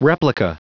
Prononciation du mot replica en anglais (fichier audio)
Prononciation du mot : replica